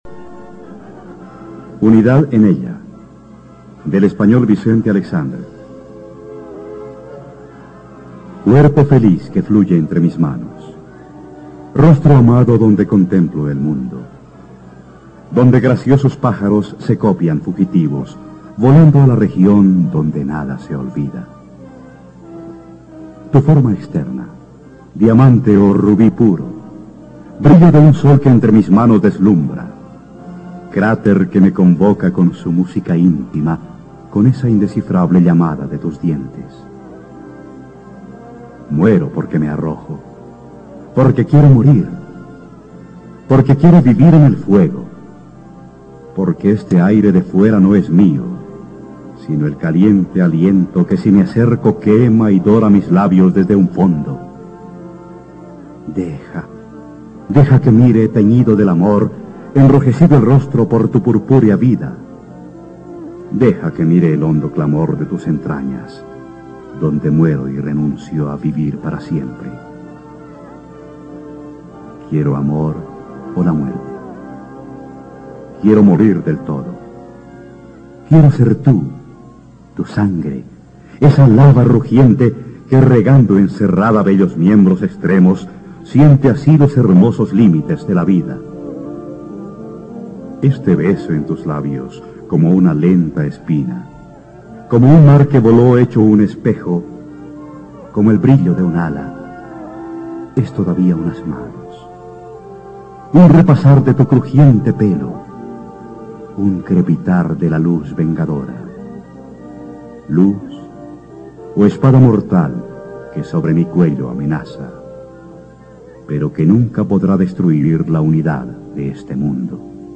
Unidad en ella, poema recitado de Vicente Aleixandre.mp3